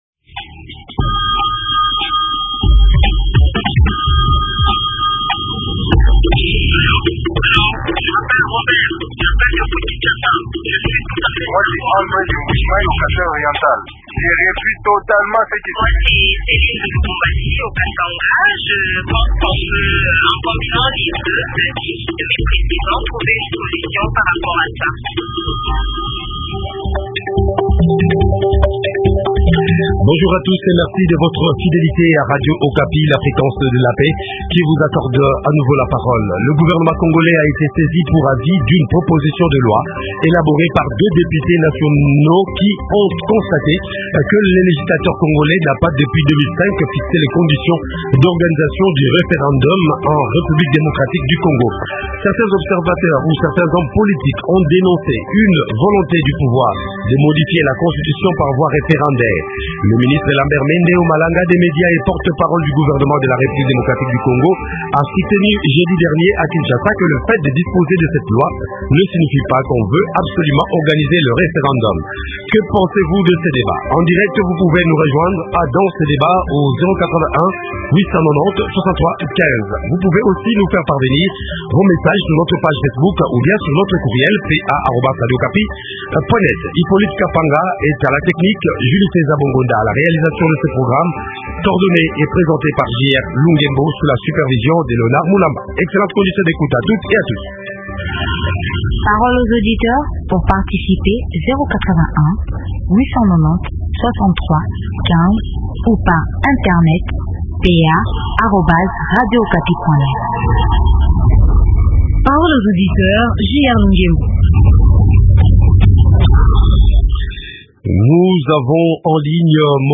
Invité : Lucain Mwadiavita, l’un de deux auteurs de la proposition